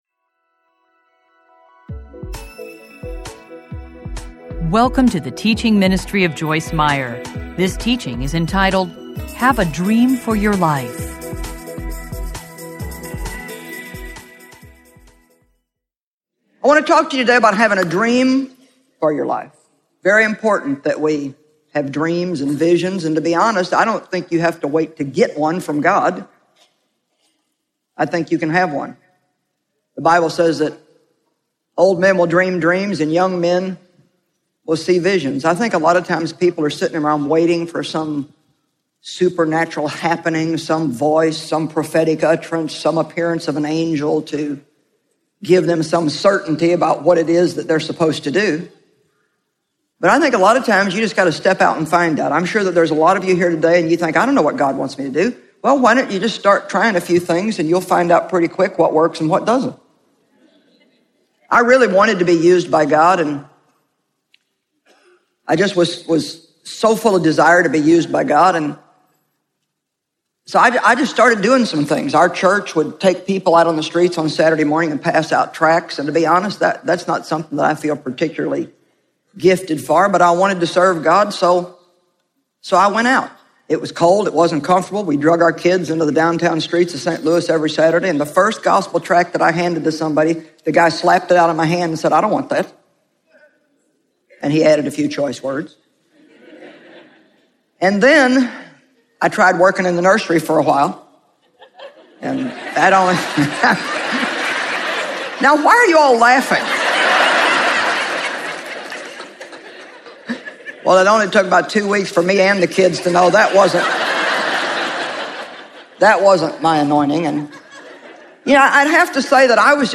Have a Dream for Your Life Teaching Series Audiobook
Narrator
Joyce Meyer
0.92 Hrs. – Unabridged